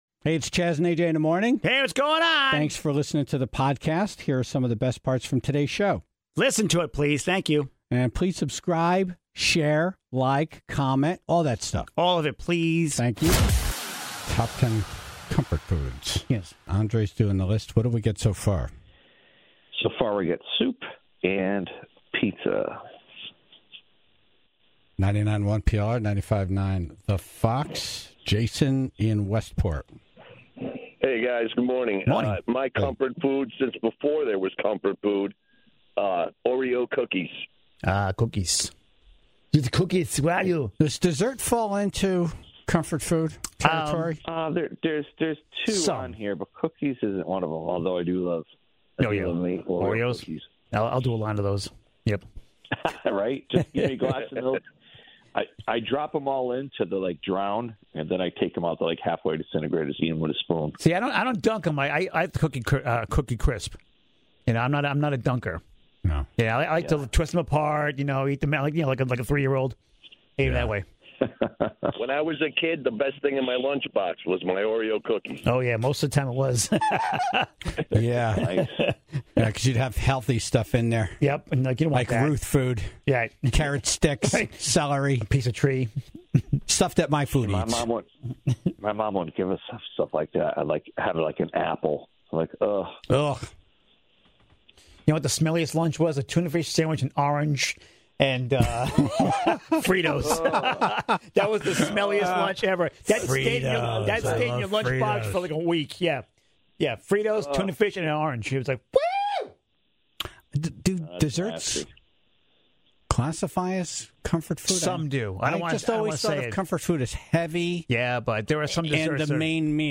(16:32) East Haven's Mayor Joe Carfora was on to talk about the election and then the Tribe called in a story about getting stuck on the Small World ride at Disney, and how the song was easily the worst part of the inconvenience.